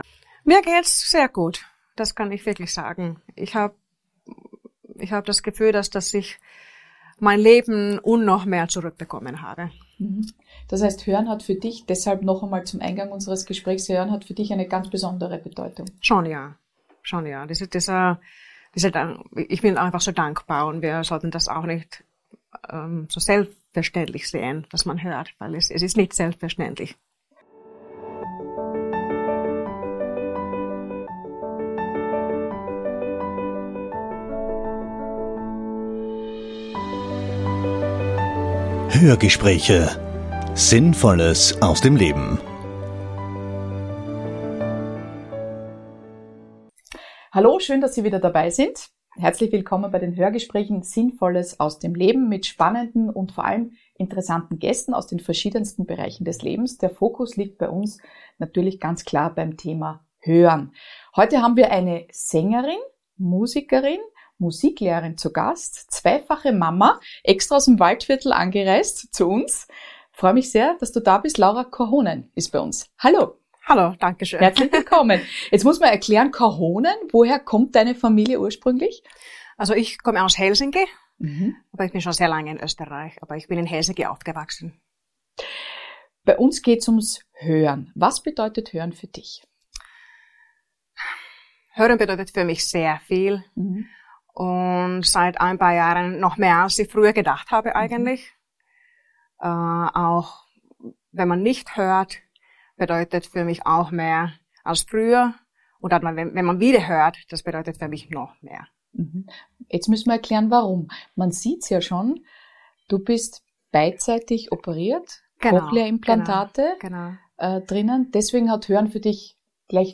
Erleben Sie ein sehr bewegendes Hörgespräch mit einer äußerst liebenswerten Musikerin auf ihrem Weg zurück zum Hören!